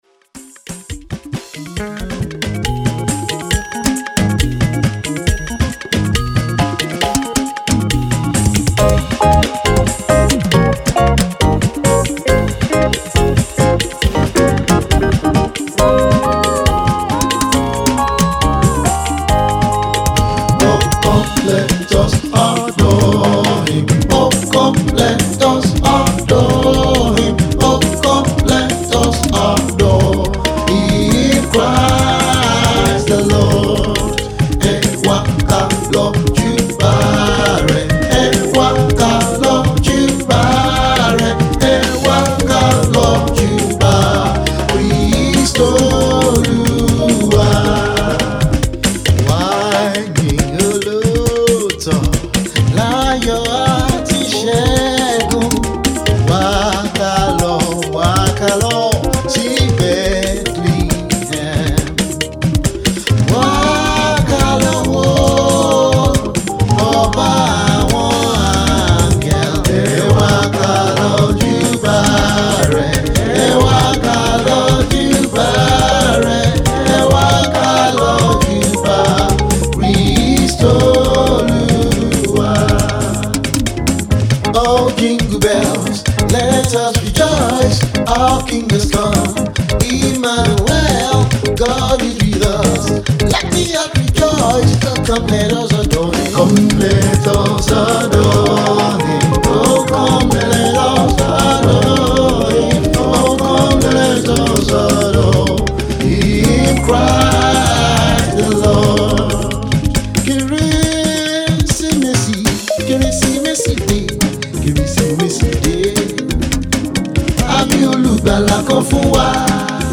which will put you on your feet dancing, with a
traditional African Groove that everyone loves.
Christmas songs embellished with African traditional
percussive instruments not loosing the originality and